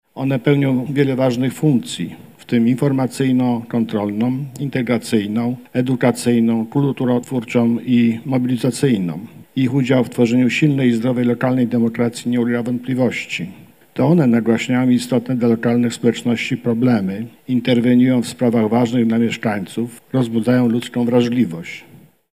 Spotkanie otworzył marszałek województwa mazowieckiego Adam Struzik. Marszałek przypomniał jaka jest najważniejsza rola mediów lokalnych.